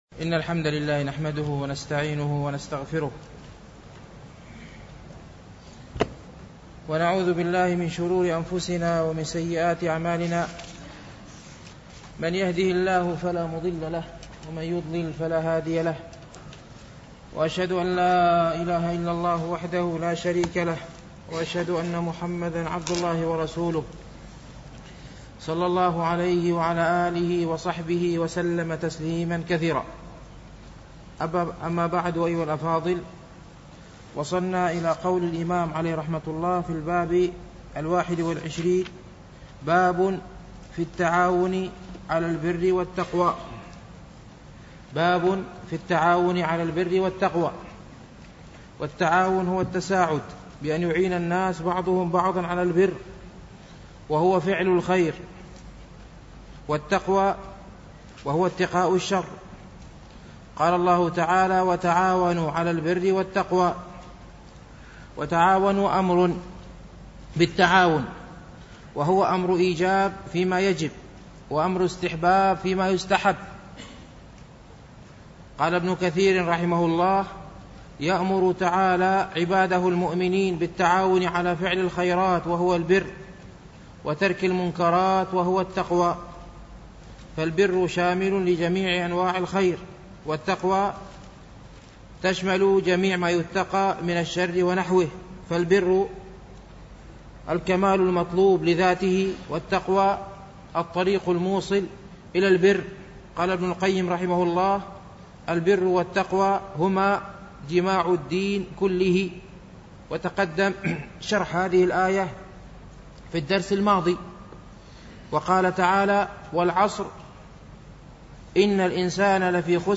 شرح رياض الصالحين - الدرس السادس والثلاثون